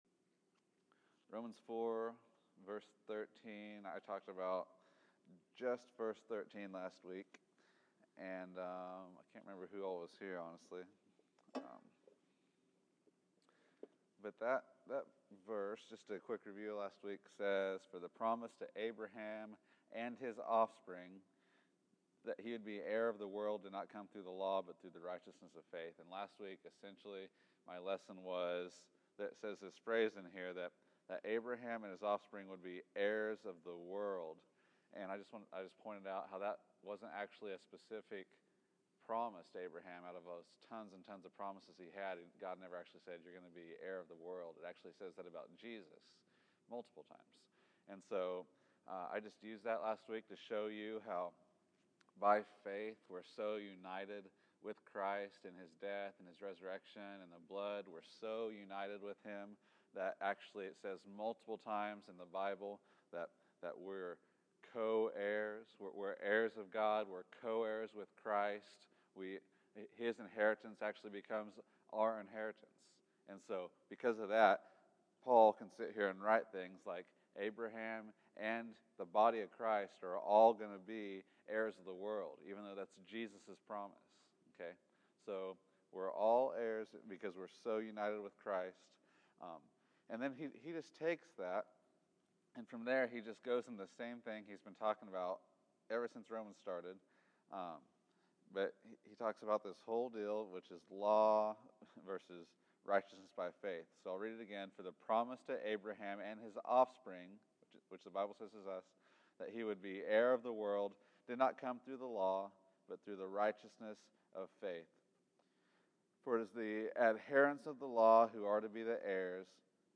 Romans 4:13-21 May 03, 2015 Category: Sunday School | Location: El Dorado Back to the Resource Library Abraham’s example of faith.